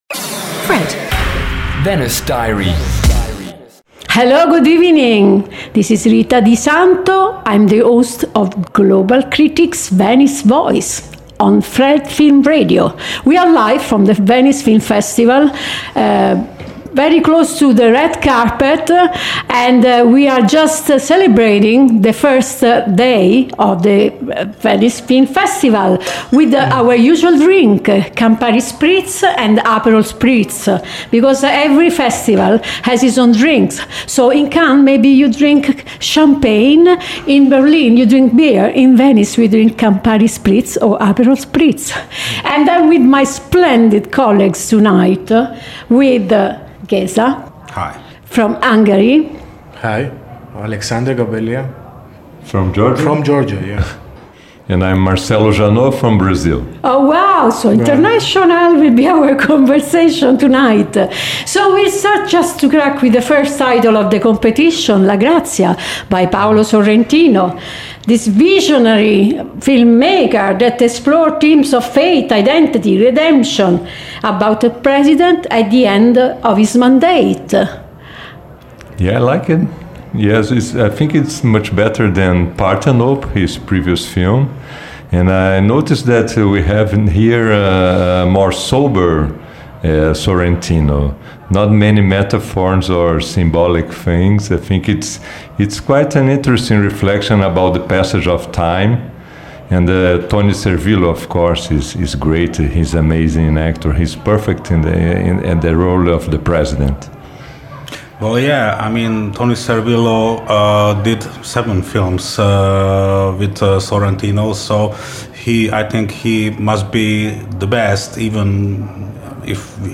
Each episode takes listeners inside the Festival with exclusive and thoughtful conversations with leading international film critics, and in-depth analysis of the year’s most anticipated films.